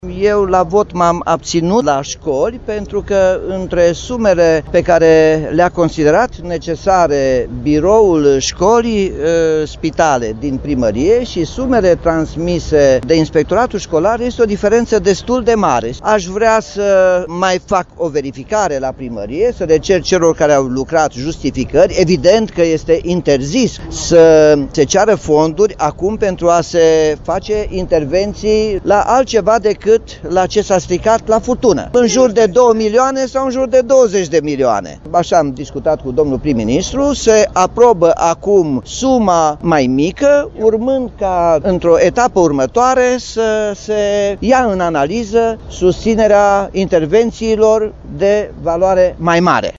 Primarul municipiului Timişoara, Nicolae Robu, a declarat că s-a abţinut la vot, întrucât între sumele avansate de Biroul şcoli, spitale din Primăria Timişoara şi sumele transmise de Inspectoratul Şcolar este o diferenţă, chiar dacă vorbim doar despre o estimare.